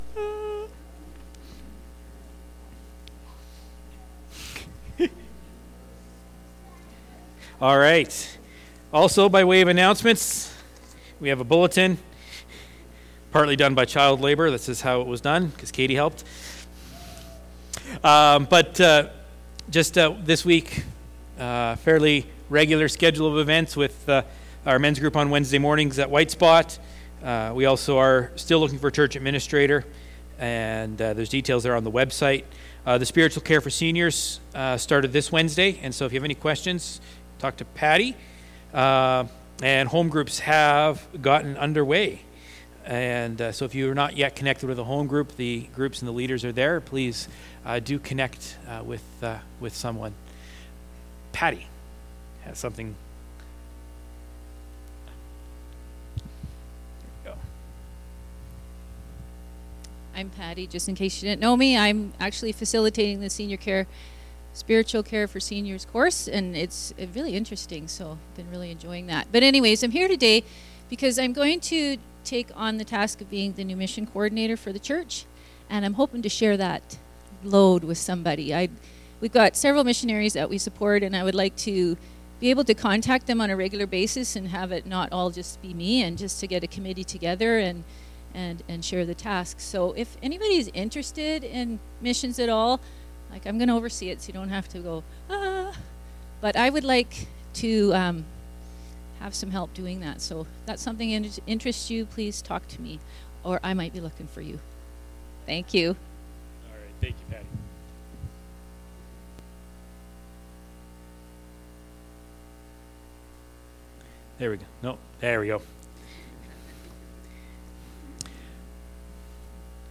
Sermons | Central Fellowship Baptist Church